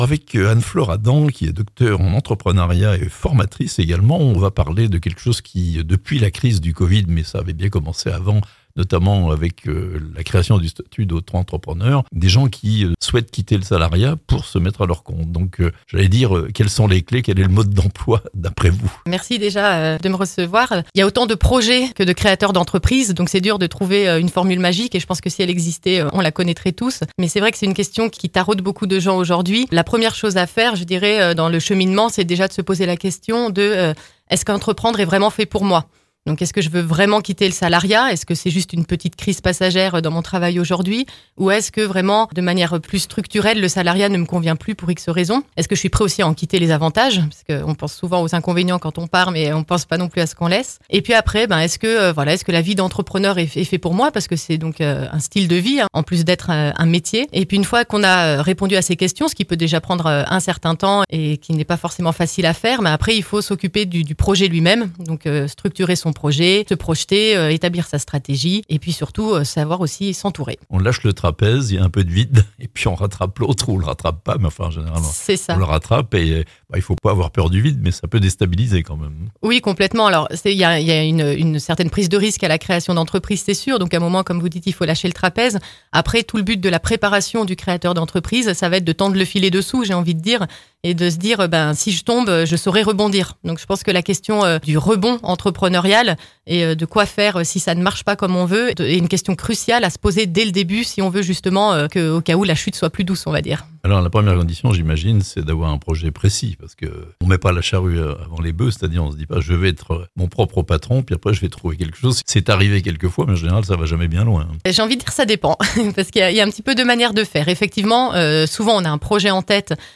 Devenir entrepreneur en 2022 (interview)